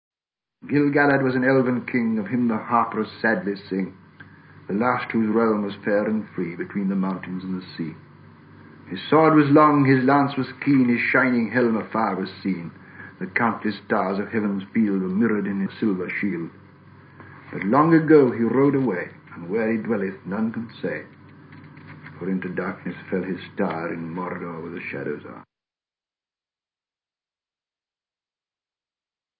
textes lus par le Maître himself, un collector !